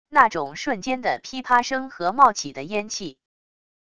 那种瞬间的噼啪声和冒起的烟气wav音频